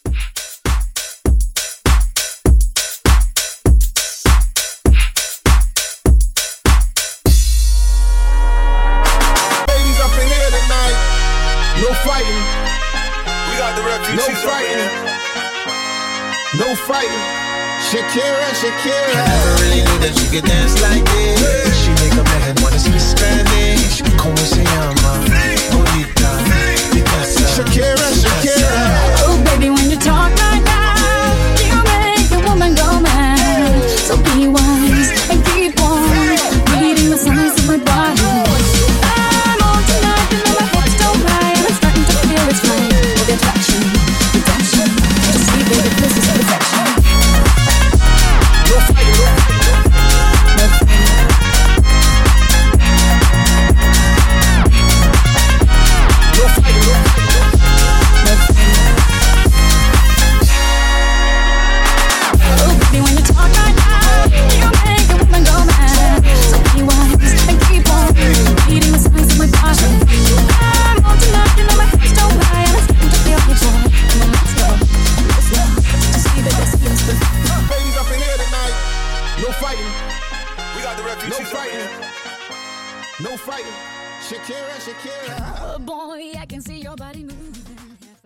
Genre: 90's Version: Clean BPM: 97 Time